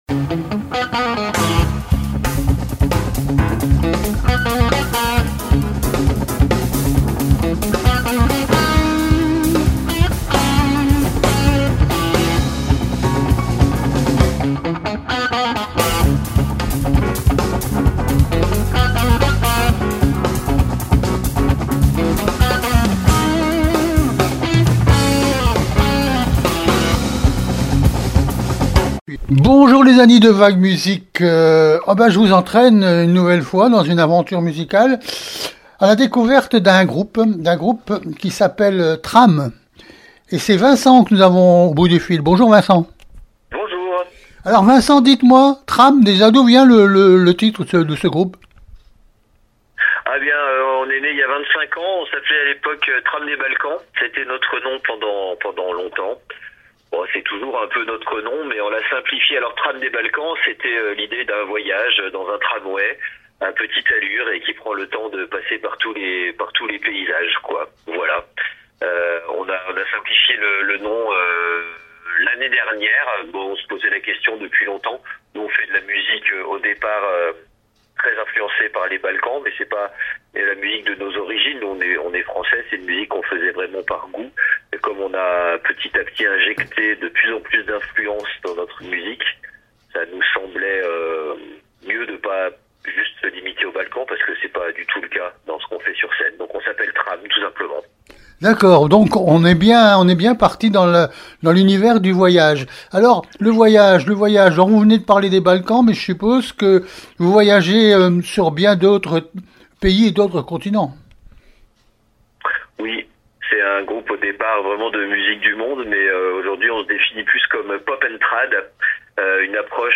TRAM-VAG MUSIC interview du 2 février 2026